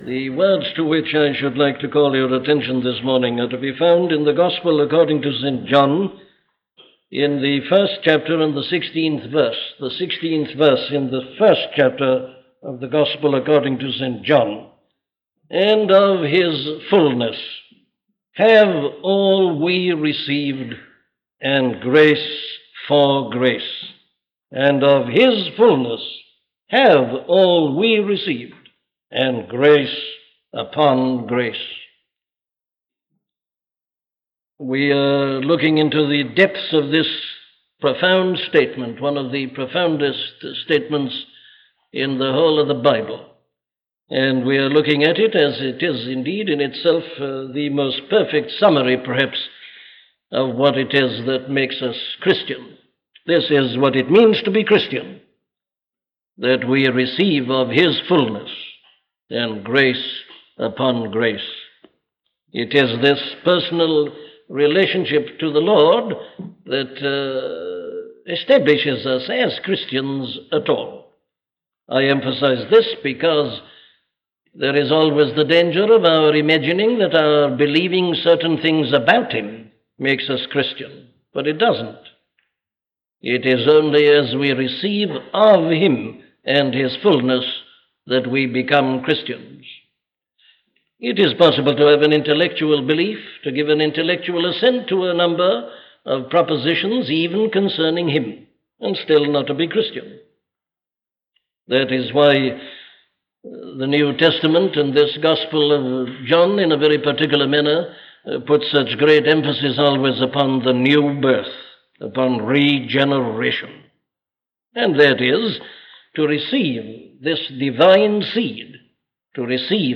Glorification - a sermon from Dr. Martyn Lloyd Jones
Listen to the sermon on John 1:16 'Glorification' by Dr. Martyn Lloyd-Jones